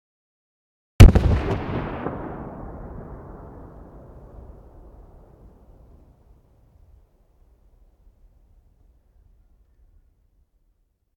mortar_distant.ogg